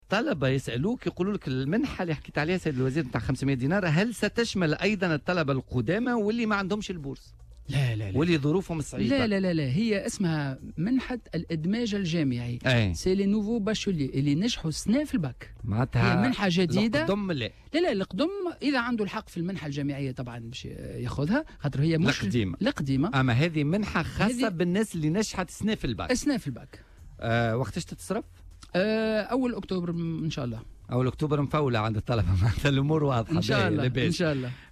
وأوضح خلال استضافته اليوم الخميس 20 سبتمبر 2018 في "بوليتيكا"، أن المنحة ستصرف مع بداية شهر أكتوبر القادم، مؤكدا أنها لن تشمل الطلبة القدامى.